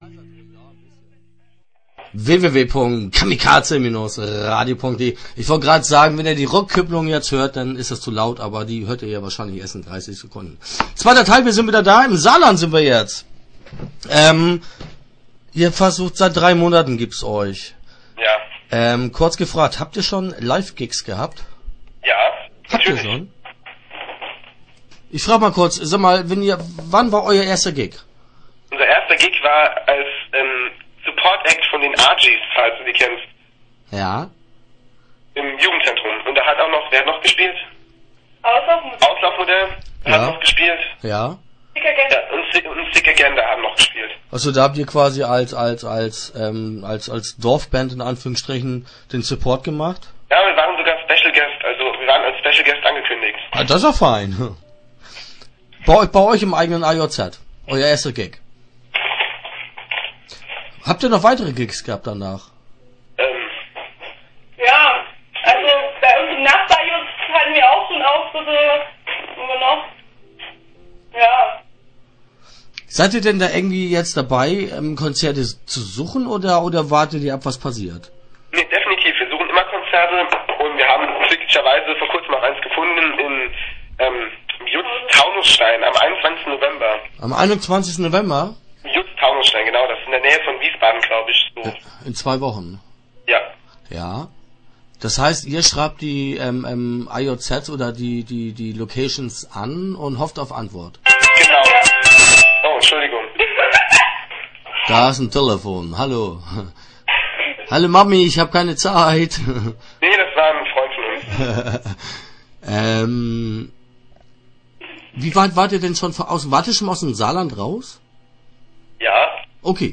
Start » Interviews » Upfluss